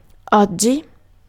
Ääntäminen
IPA: /ak.tɥɛl.ˈmɑ̃/